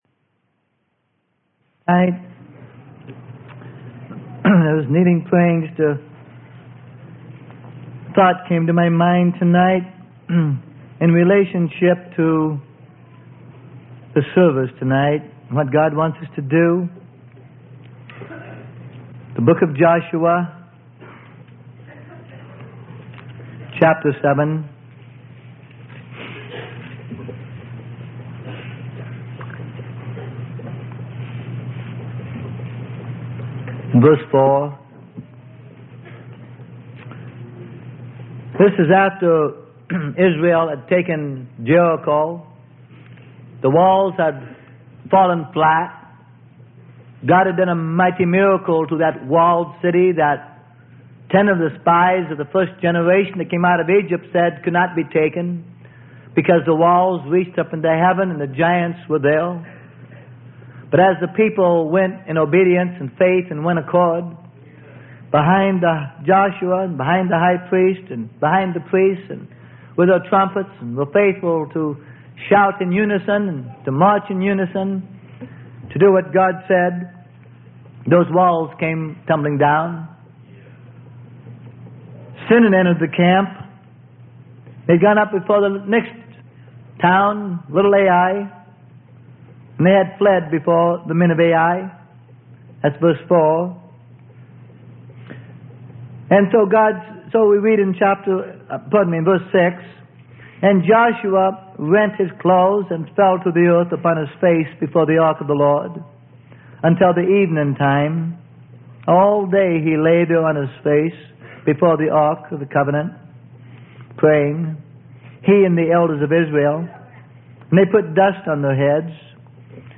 Sermon: Up, Sanctify The People.